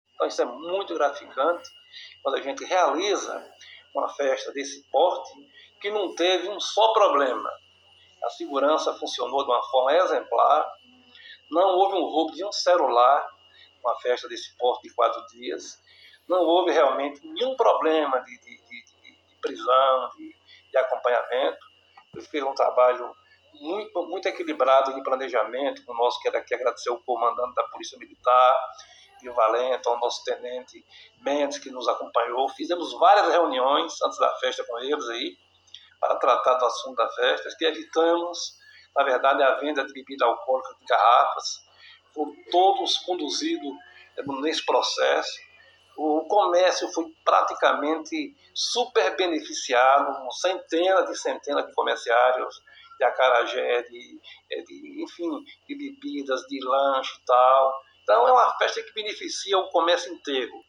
O prefeito de Valdente Ubaldino Amaral (Avante) recebeu o Calila Notícias na manhã desta quinta-feira, 27, em seu gabinete onde fez uma avaliação geral da festa de São João, ou seja, o tradiconal Arraiá do Boi Valente que aconteceu na Praça do Forródromo que aconteceu entre os dias 21 e 24.